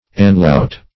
Search Result for " anlaut" : The Collaborative International Dictionary of English v.0.48: Anlaut \An"laut`\, n. [G.; an on + laut sound.]